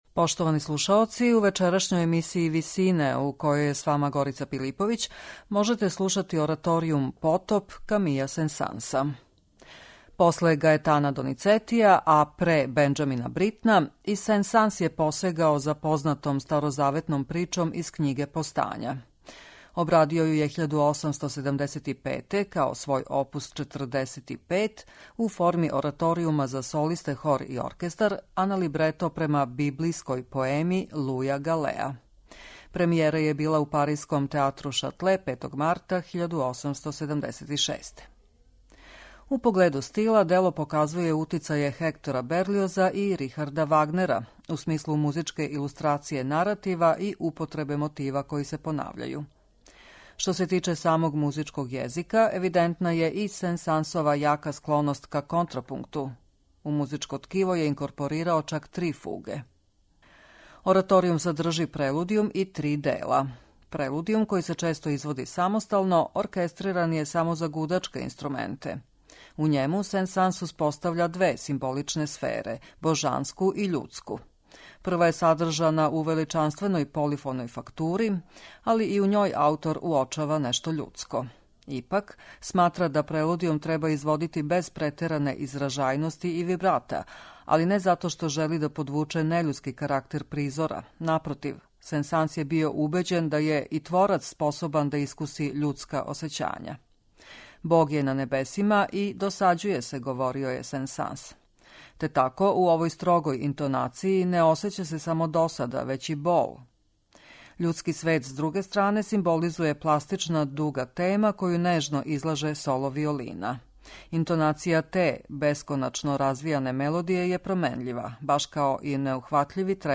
Обрадио ју је 1875, као свој опус 45, у форми ораторијума за солисте, хор и оркестар, а на либрето према „библијској поеми" Луја Галеа.
Што се тиче самог музичког језика, евидентна је и Сен-Сансова јака склоност ка контрапункту - у музичко ткиво је инкорпорирао чак три фуге.